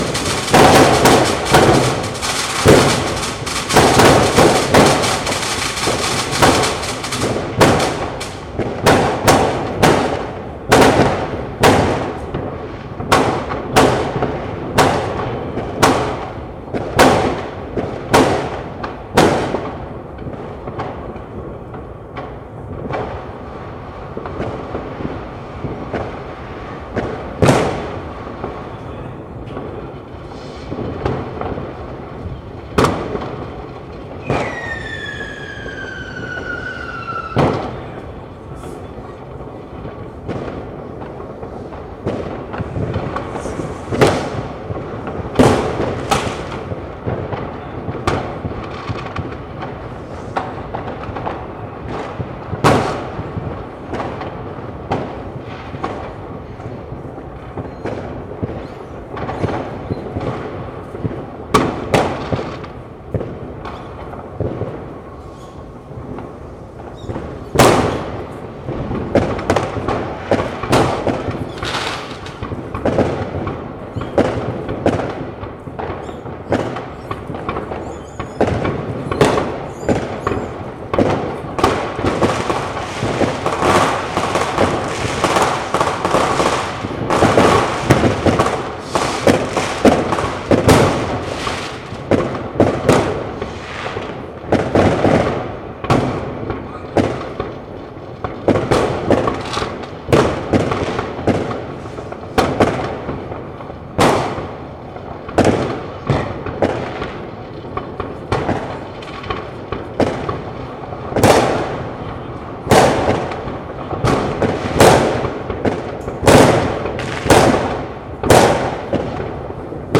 Let's start with what my 24/7 outside Mics, the Clippy EM 272, attached to my window have captured. I sadly didn't have the time to setup better quallity thann opus with meh settings which where already in place, but I'll post some of the stuff I captured with the Zoom H1E as well. this are 5 minutes which give an overviewe quite well, no processing or anything. This was right 5 minutes after midnight, so 12:05 to 12:10.